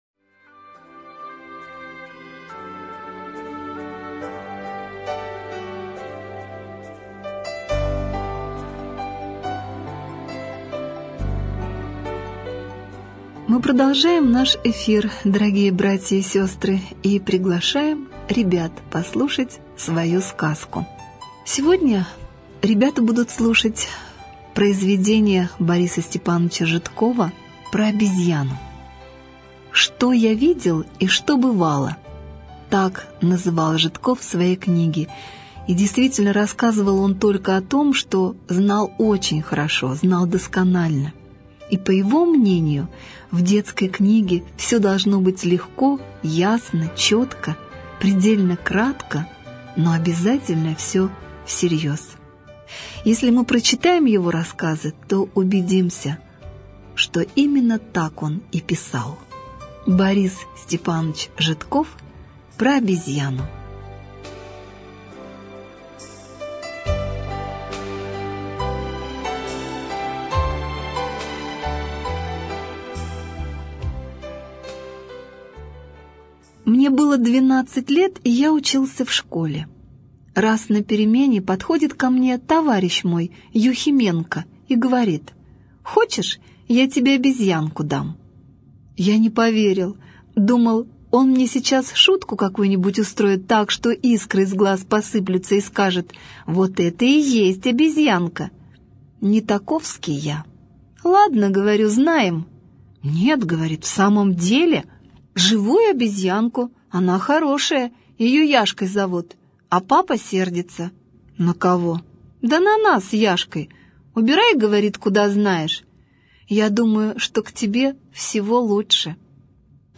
Про обезьяну - аудио рассказ Житкова Б.С. Мальчику его друг подарил живую обезьянку. Мальчик принес ее домой, и она сразу начала хулиганить.